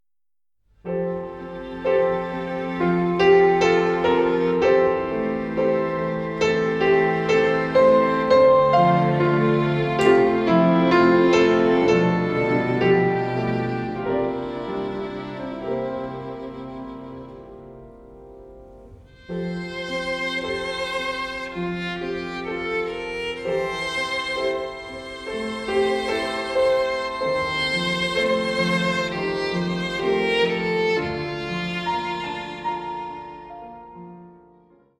Vokalensemble, Klavier